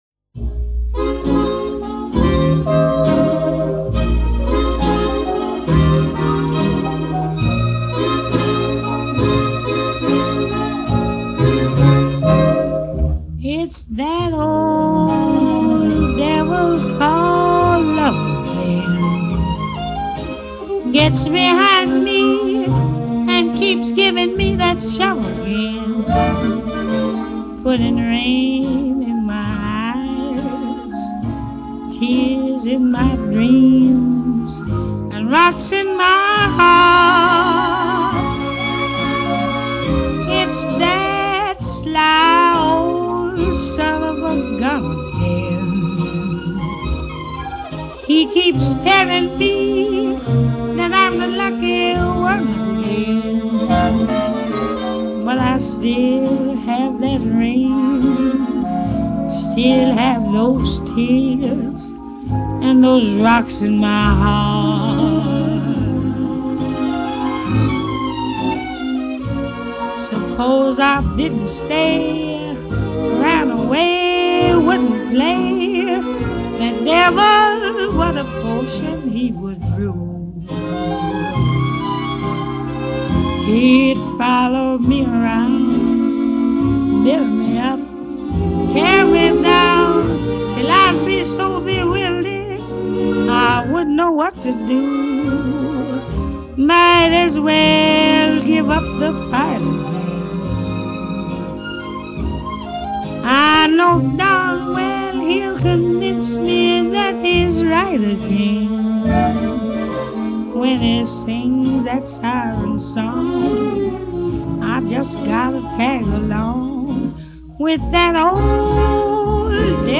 Original recordings | Genre: Vocal, jazz, latin jazz